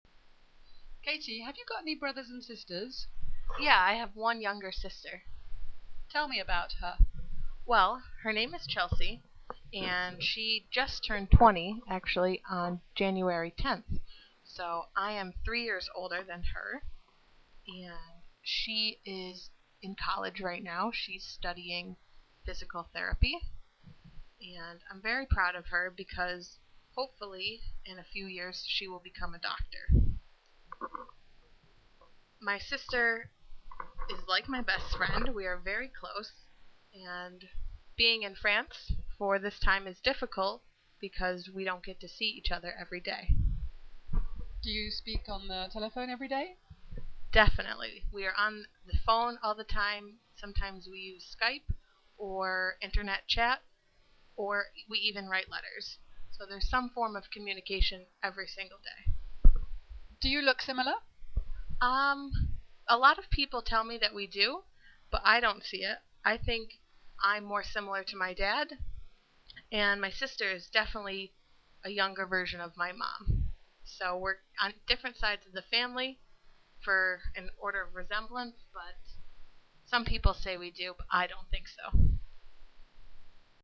Britannique / Américain